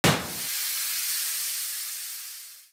WheelDeflate.wav